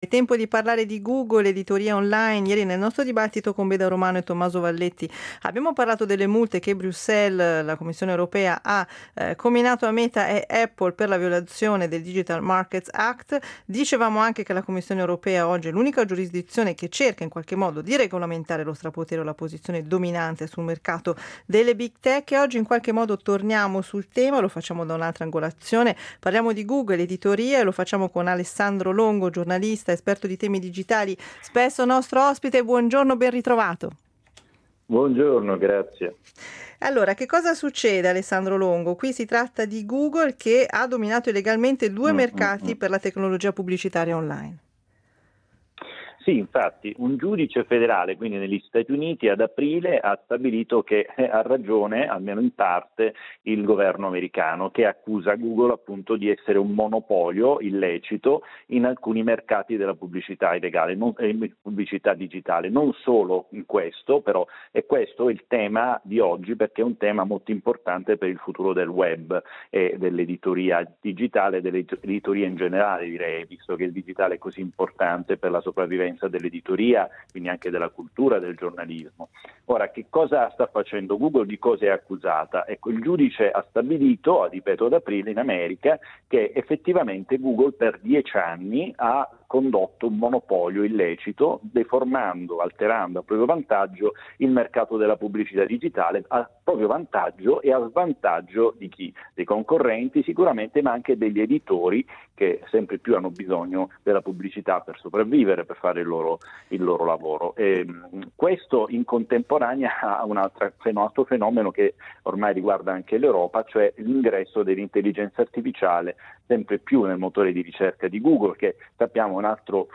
Google, di Alphabet, ha dominato illegalmente due mercati della tecnologia pubblicitaria online, è quanto ha stabilito un giudice infliggendo un altro colpo al gigante tecnologico e aprendo la strada ai procuratori antitrust statunitensi per chiedere lo smembramento dei suoi prodotti pubblicitari. Ne abbiamo parlato con il giornalista ed esperto di innovazione